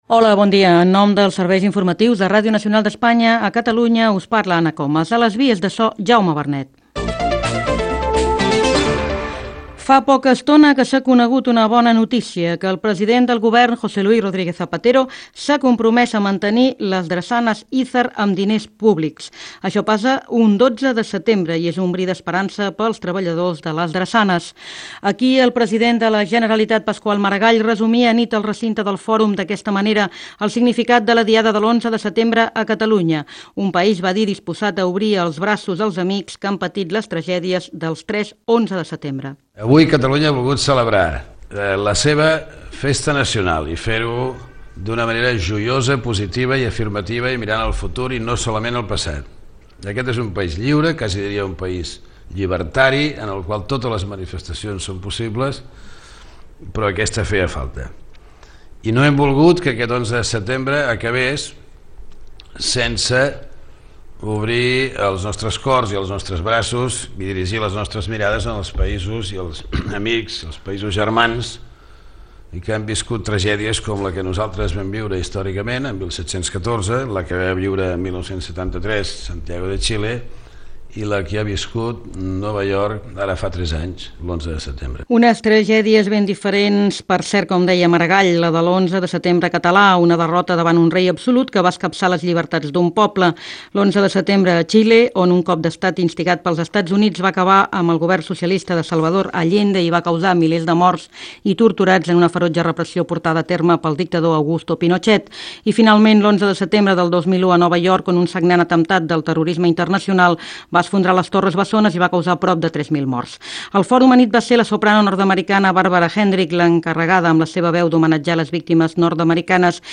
Informatiu